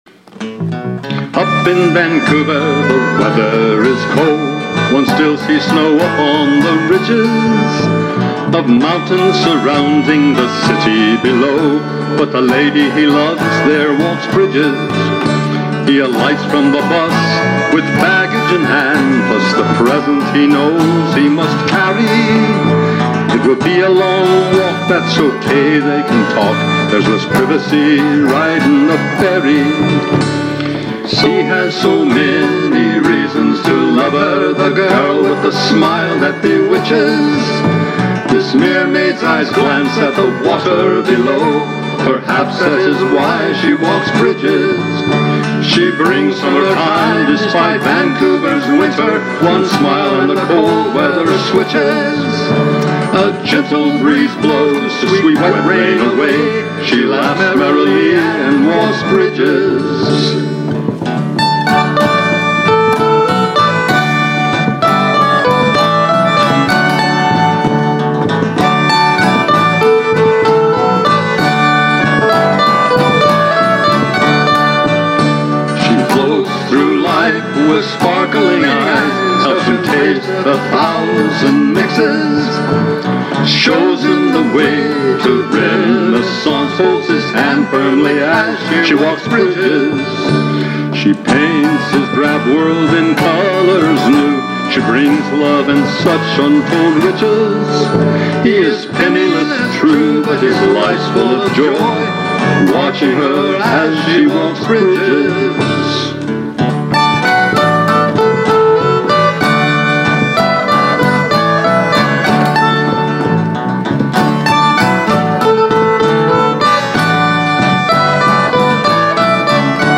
This entry was posted in Songs.
Also very much enjoyed this song almost a shanty but also a blue grass/country sound and it was neat that he worked some of your poetry in as lyrics but also repeated the line about her walking bridges.